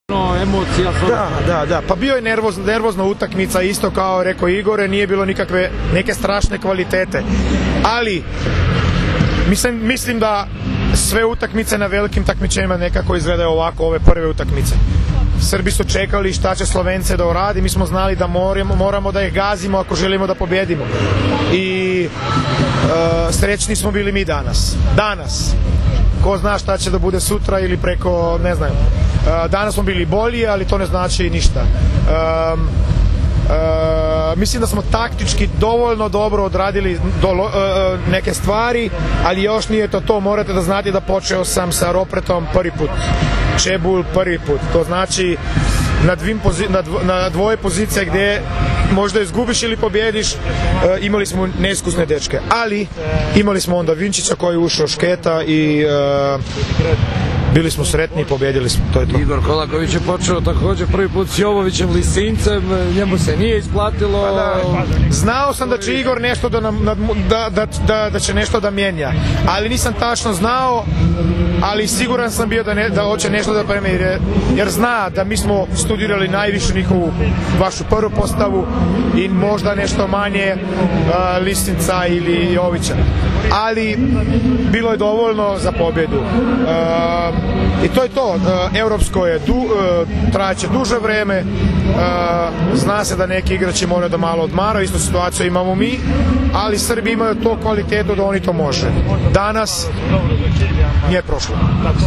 IZJAVA LUKE SLABEA